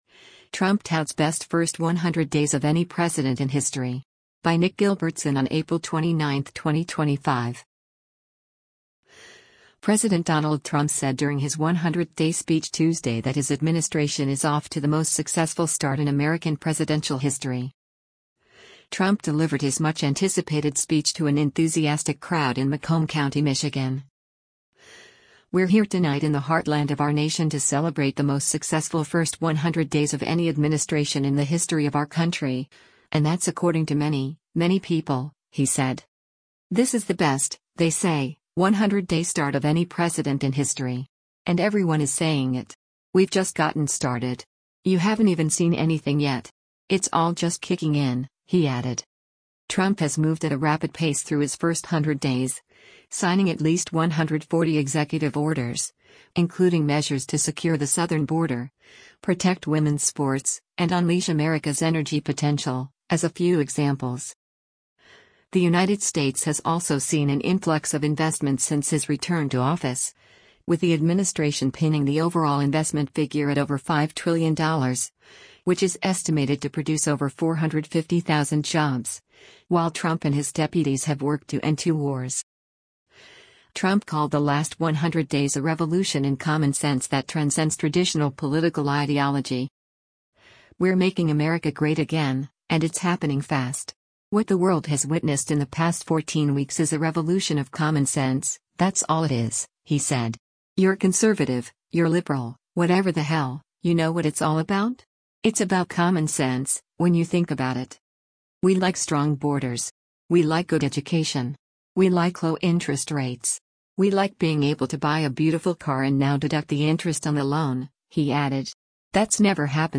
Trump delivered his much-anticipated speech to an enthusiastic crowd in Macomb County, Michigan.